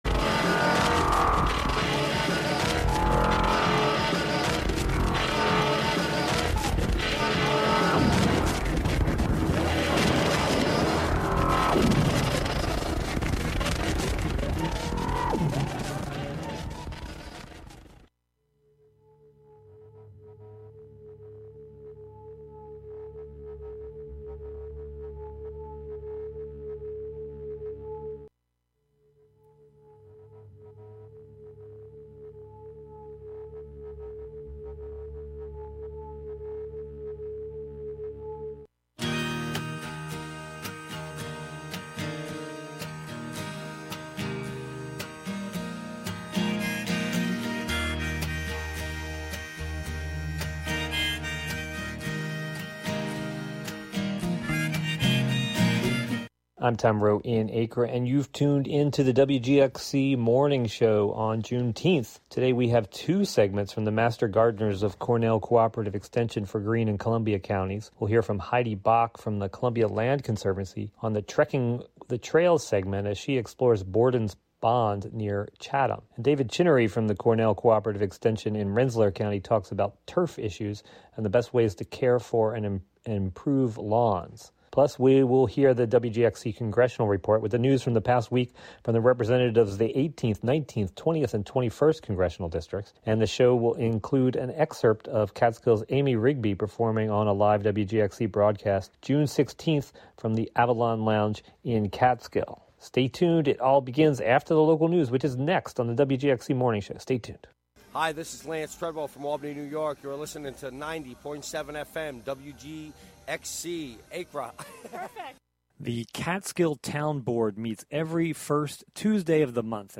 Plus, tune in for the WGXC Congressional Report, with the news from the past week from the representatives of the 18th, 19th, 20th, and 21st Congressional Districts. And the show will also include an excerpt of Catskill's Amy Rigby performing on a live WGXC broadcast June 16 from The Avalon Lounge in Catskill. The "WGXC Morning Show" is a radio magazine show featuring local news, interviews with community leaders and personalities, reports on cultural issues, a rundown of public meetings and local and regional events, with weather updates, and more about and for the community, made mostly through volunteers in the community through WGXC.